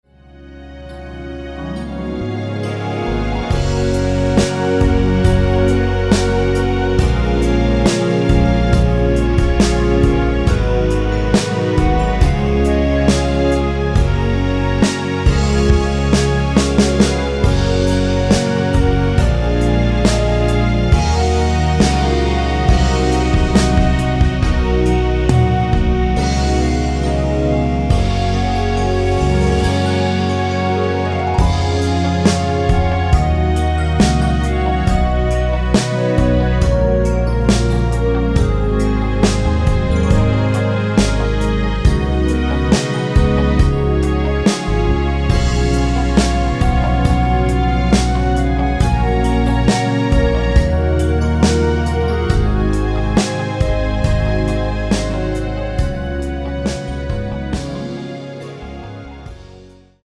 원키에서(-1)내린 (짧은편곡) 멜로디 포함된 MR입니다.
F#
앞부분30초, 뒷부분30초씩 편집해서 올려 드리고 있습니다.
중간에 음이 끈어지고 다시 나오는 이유는